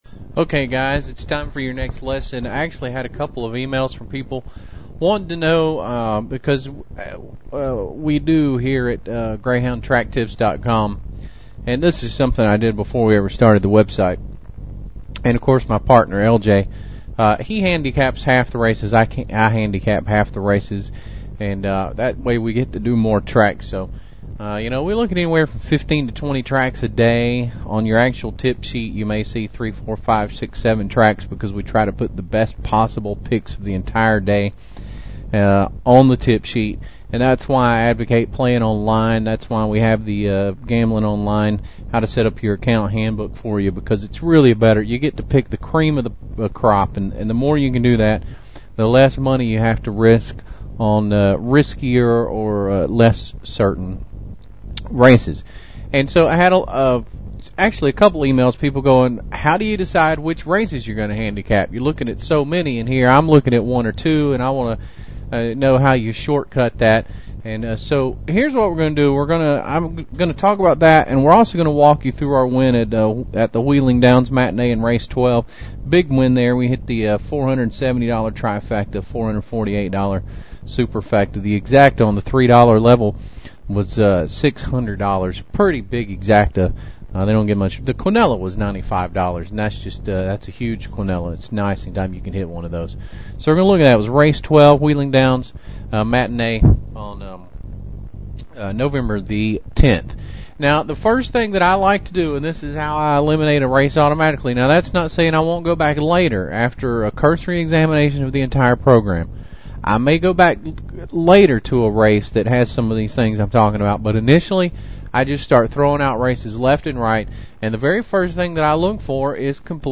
Lesson 3